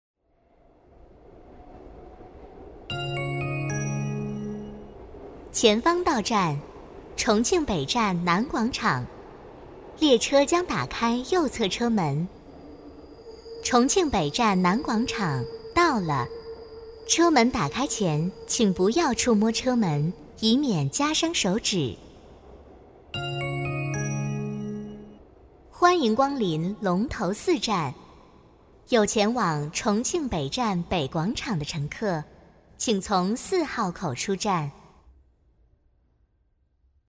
女国132_多媒体_广播提示_重庆地铁报站.mp3